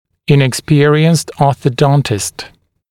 [ˌɪnɪk’spɪərɪənst ˌɔːθə’dɔntɪst][ˌиник’спиэриэнст ˌо:сэ’донтист]неопытный врач-ортодонт